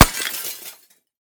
6f19f2c70f Divergent / mods / JSRS Sound Mod / gamedata / sounds / material / bullet / collide / glas01hl.ogg 39 KiB (Stored with Git LFS) Raw History